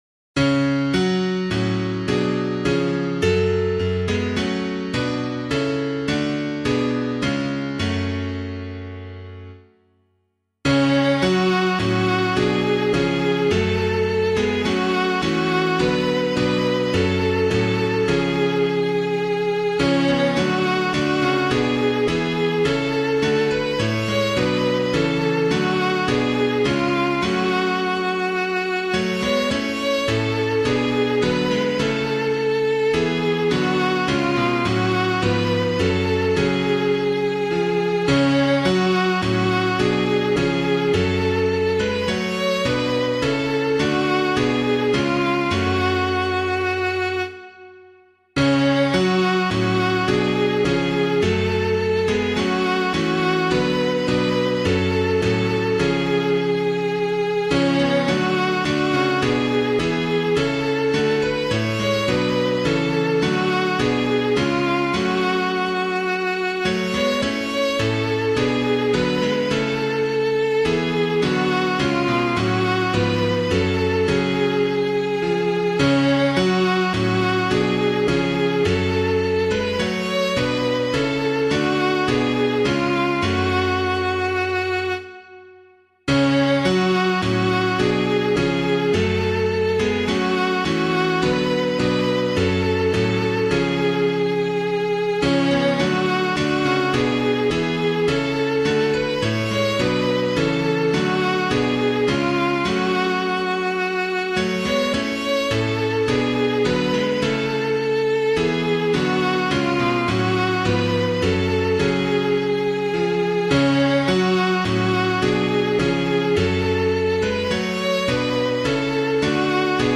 Composer:    Welsh folk melody, Llwybrau Moliant, 1872,
piano
O God of Every Nation [Reid Jr. - LLANGLOFFAN] - piano.mp3